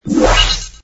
map_tabs_open.wav